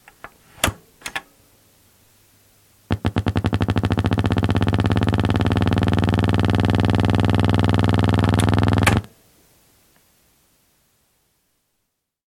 Broken TV